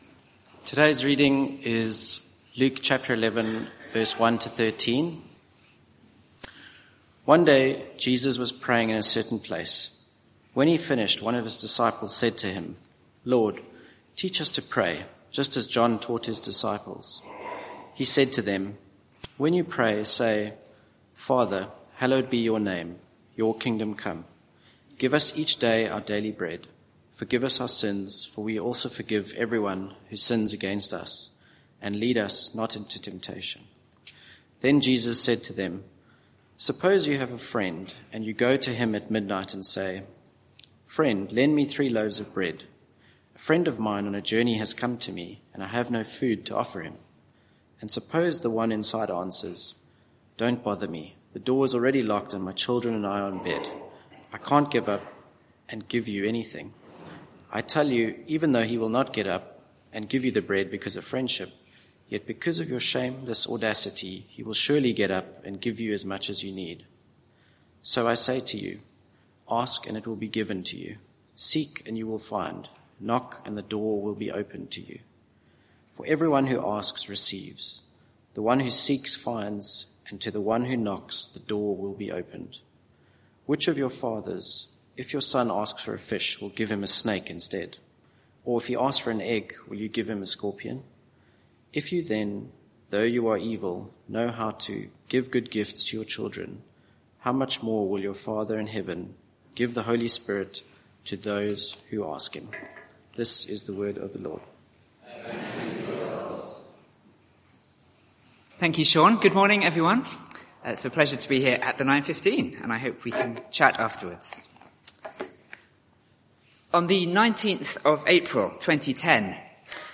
Media for 9:15am Service on Sun 18th May 2025 09:15 Speaker
Sermon (audio) Search the media library There are recordings here going back several years.